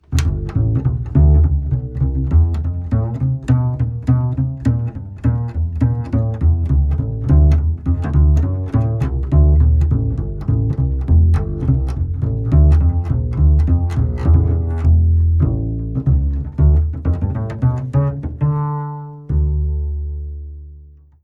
Contrebasse 1/2 massive
Une contrebasse 1/2 , équipée d' un fishman full circle pour les petits gigs du Dimanche et autres....très pratique et super son, bien mieux que toutes les contrebasses électriques que j'ai pu essayer.
Pizzicato